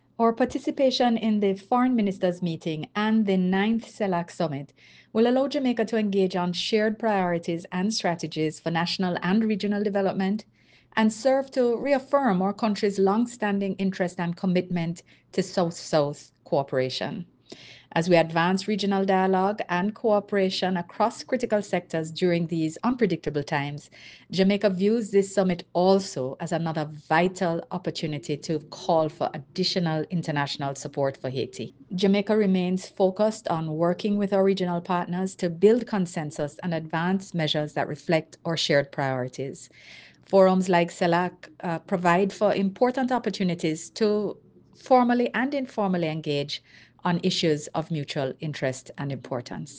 Foreign-Minister-Johnson-Smith-Speaks-on-9th-CELAC-Summit.mp3